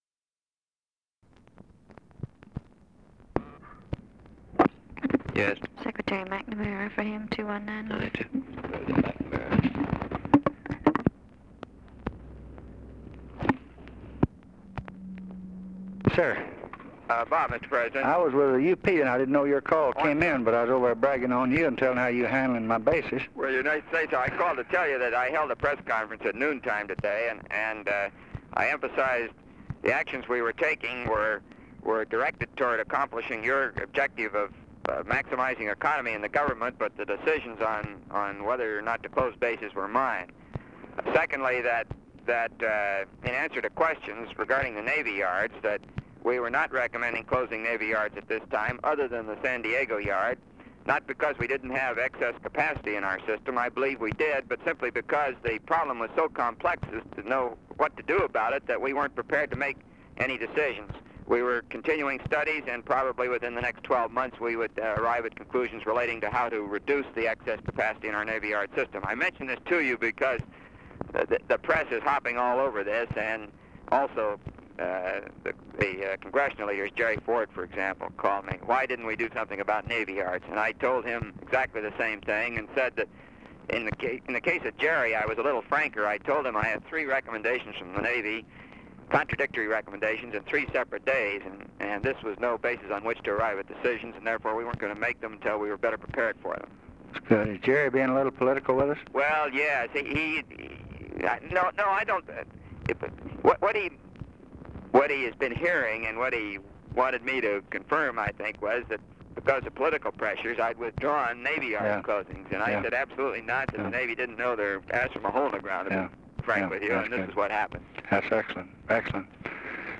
Conversation with ROBERT MCNAMARA, December 12, 1963
Secret White House Tapes